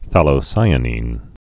(thălō-sīə-nēn, fthăl-)